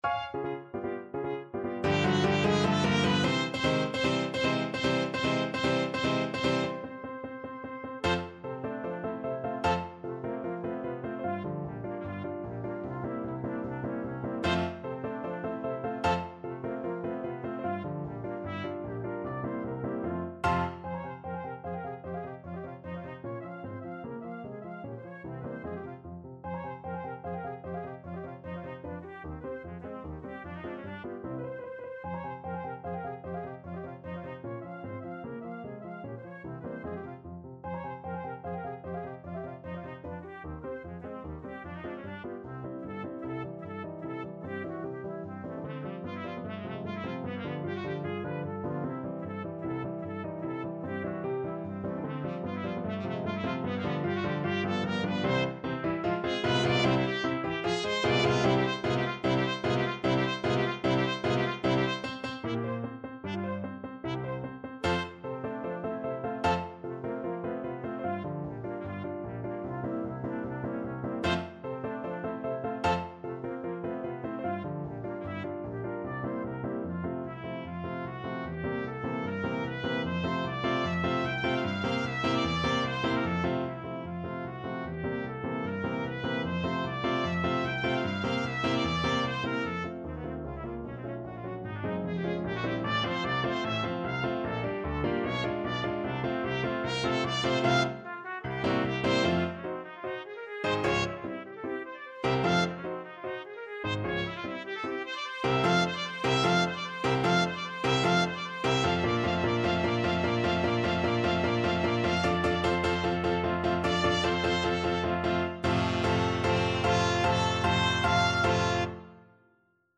Classical Ponchielli, Amilcare Galop from Dance of the Hours (from La Gioconda) Trumpet version
F major (Sounding Pitch) G major (Trumpet in Bb) (View more F major Music for Trumpet )
Allegro vivacissimo ~ = 150 (View more music marked Allegro)
2/4 (View more 2/4 Music)
Classical (View more Classical Trumpet Music)